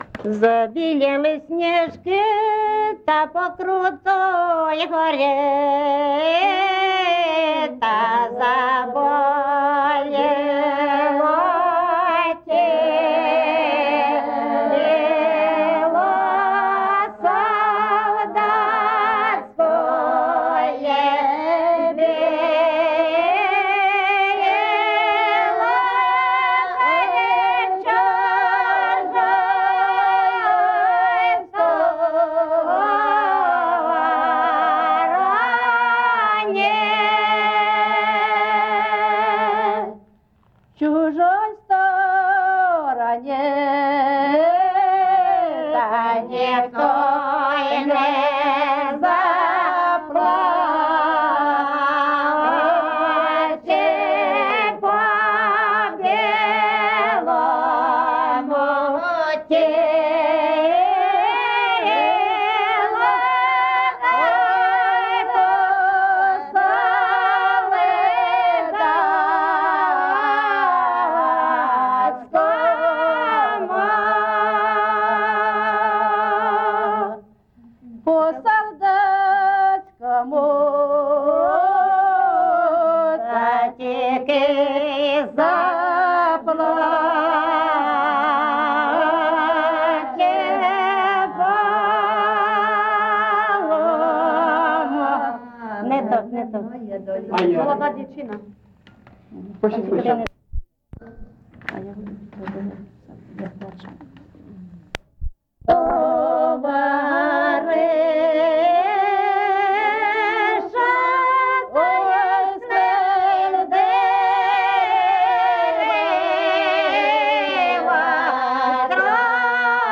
ЖанрКозацькі, Солдатські